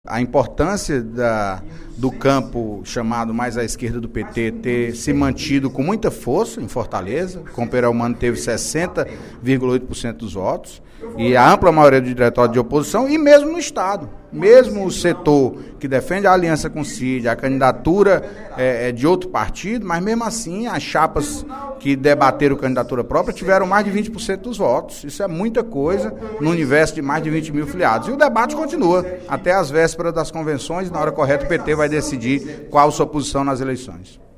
O deputado Antonio Carlos (PT) avaliou, no primeiro expediente da sessão plenária da Assembleia Legislativa desta terça-feira (12/11), o resultado das eleições internas do PT, que escolheram os novos dirigentes no Ceará.